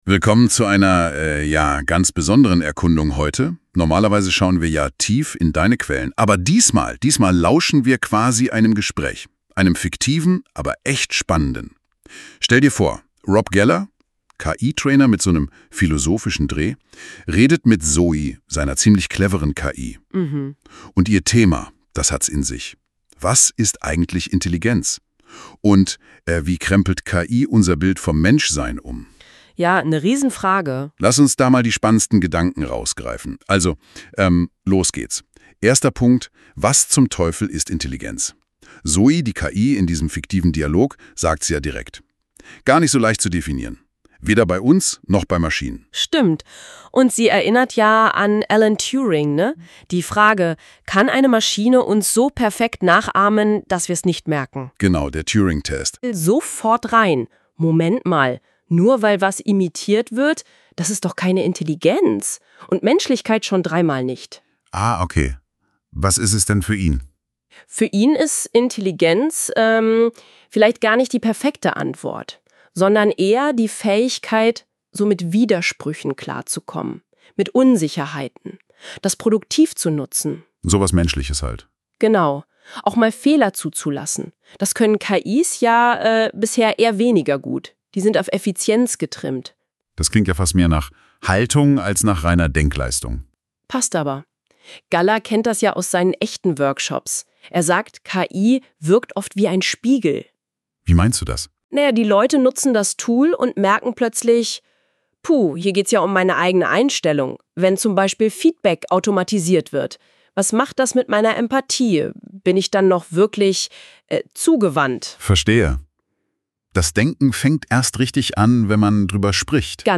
100 % KI erzeugter Podcast